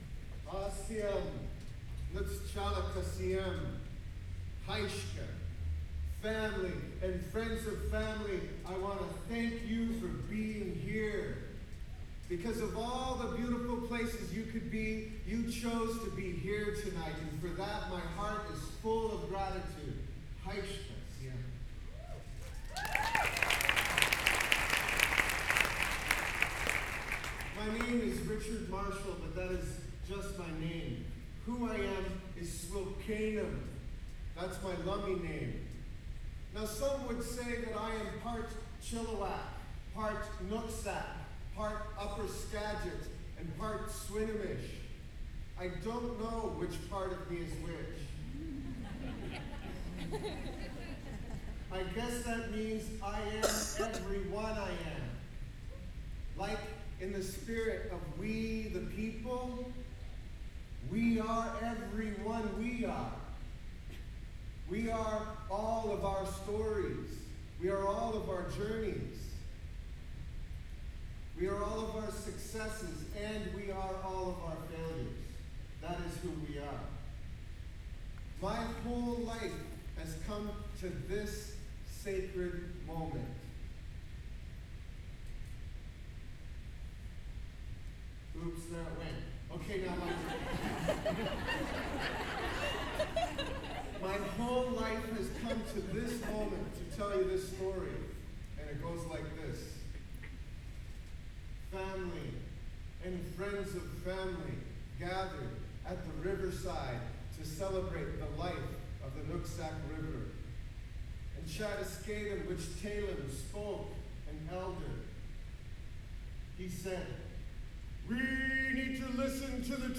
lifeblood: bootlegs: 2003-04-15: hub ballroom (university of washington) - seattle, washington (honor the earth benefit with winona laduke)